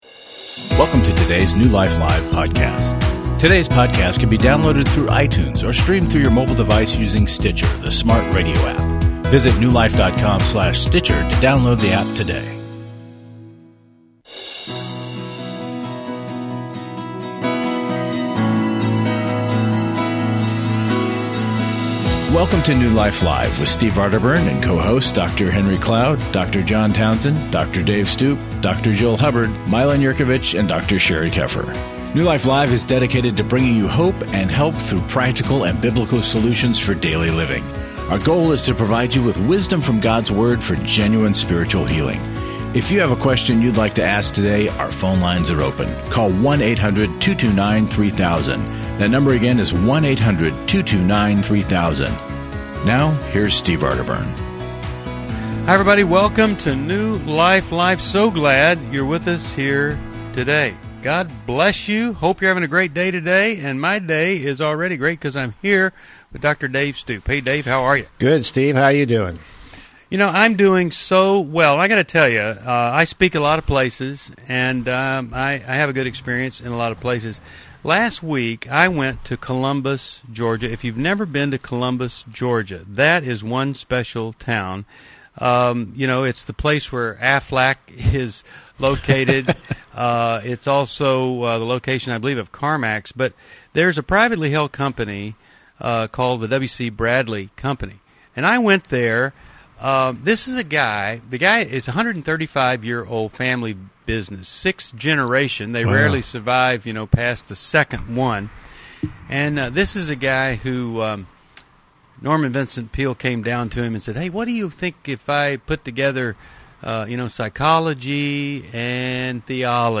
New Life Live: January 11, 2016 explores depression, anger, and forgiveness, tackling caller questions on shock treatment, church dynamics, and narcissism.